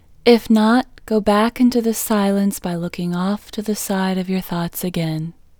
LOCATE Short IN English Female 11